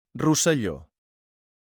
; Catalan: Rosselló [rusəˈʎo]
Ca-Rosselló.wav.mp3